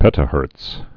(pĕtə-hûrts)